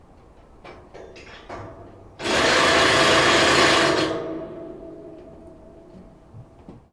钻探 " 钻探 7i
描述：所有这些都是用Zoom H2录制的。
钻孔声、敲击声、脚手架平台的声音（类似于索尼M10的录音），还有人说。
Tag: 锤击 电动工具 机械 嗡嗡声 噪音 钻探 建筑 机械 重击 工作 嗡嗡声 高层 城市